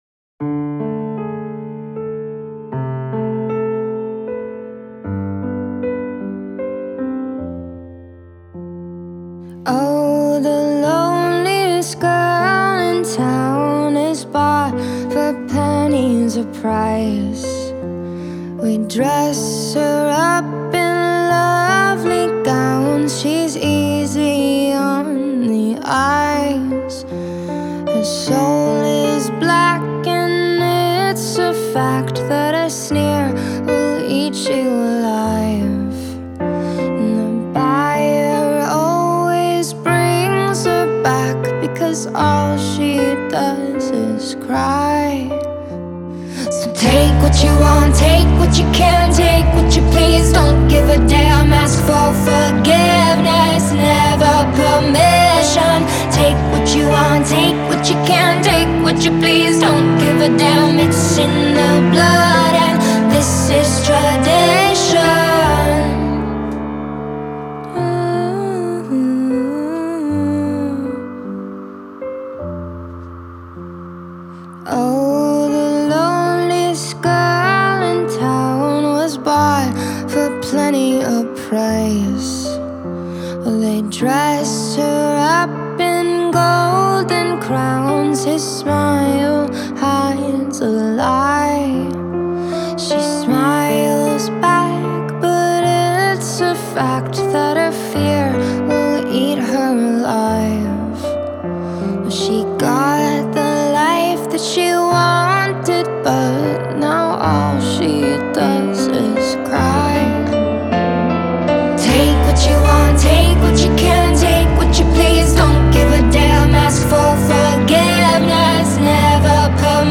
Alternative Rock, Industrial, Pop Rock